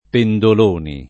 penzoloni [ pen z ol 1 ni ]